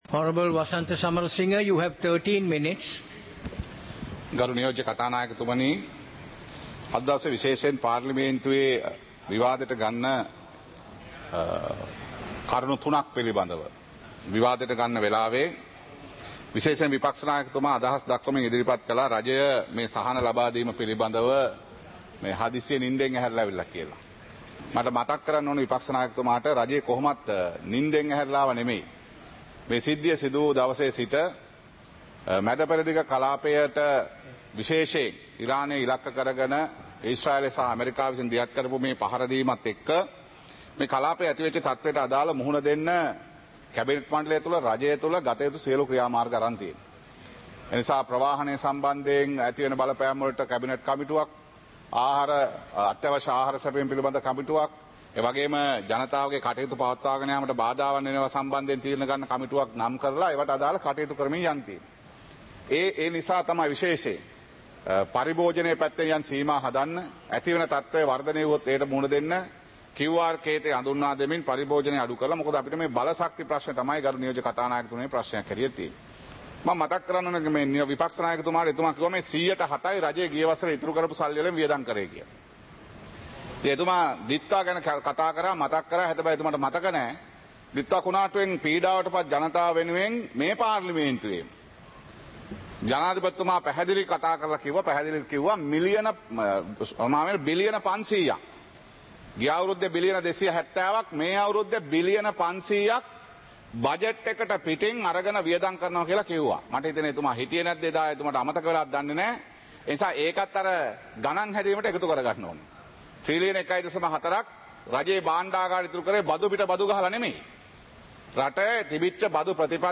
Proceedings of the House (2026-04-07)
Parliament Live - Recorded